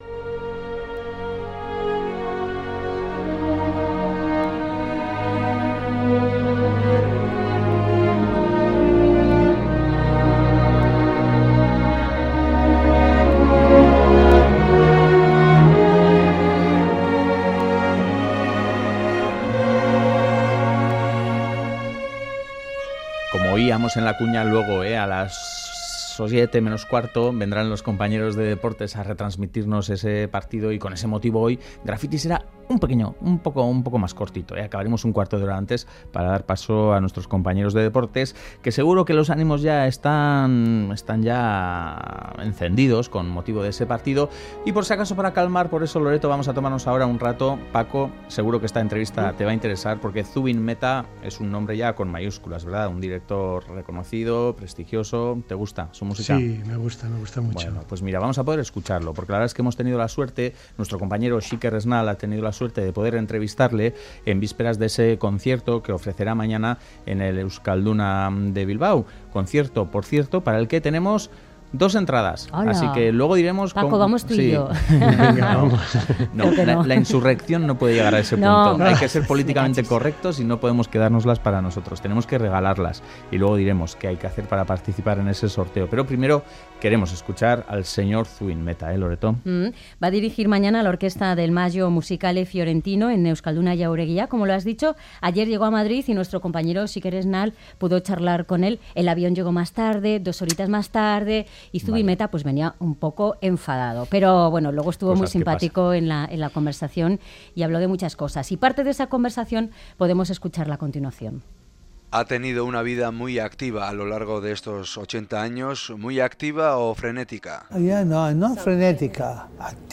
Última actualización: 15/09/2016 19:10 (UTC+2) El maestro y director de orquesta ha sido entrevistado para el programa Graffiti con motivo de su participación en el ciclo "Bilbao Puerto de Arte.